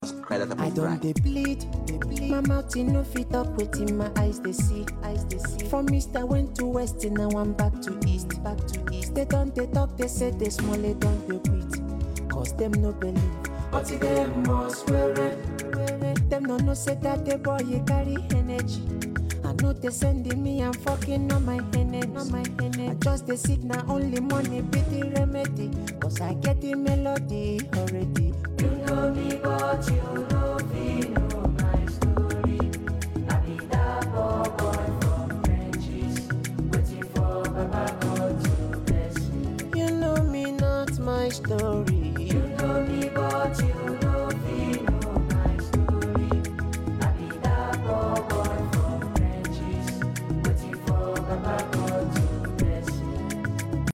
Nigerian Yoruba Fuji track
be ready to dance to the beats